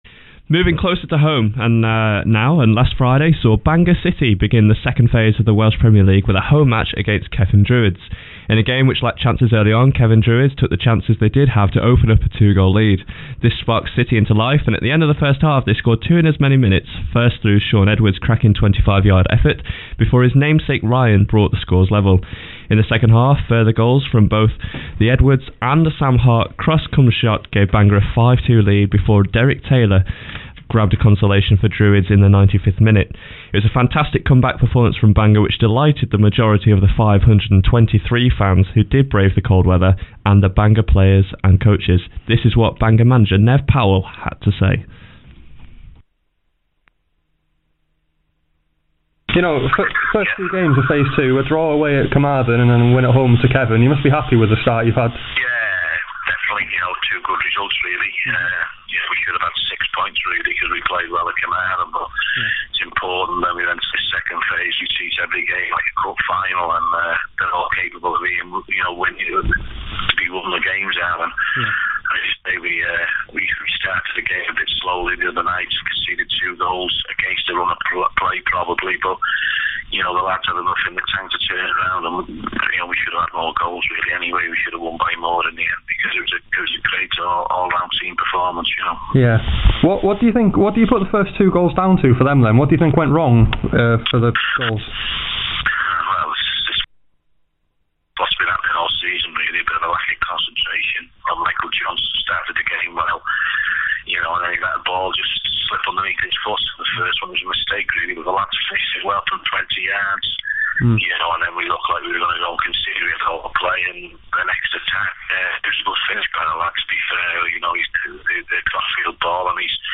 The sound quality isn't great in parts.